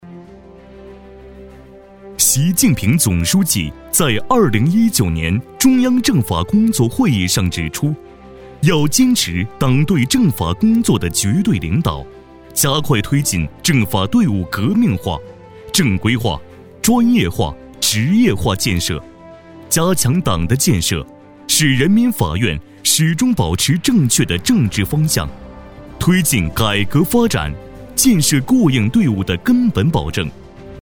C【党建】党建男135.mp3